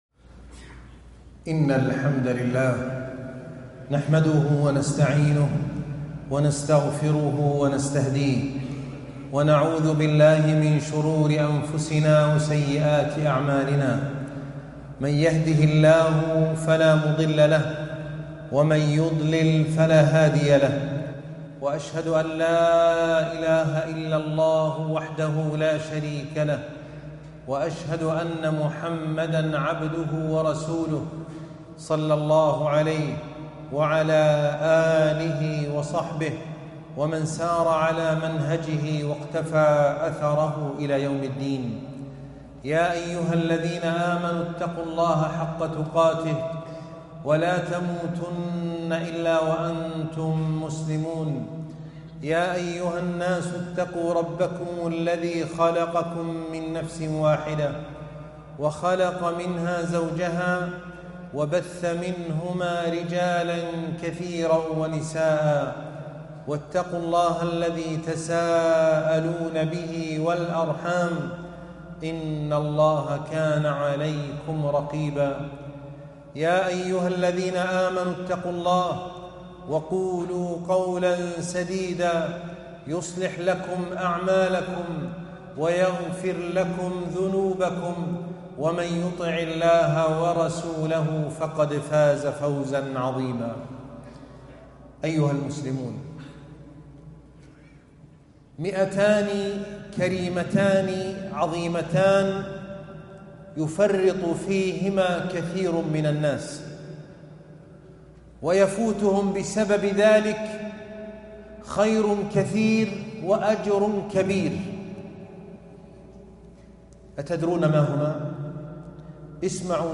مائتان عظيمتان - خطبة الجمعة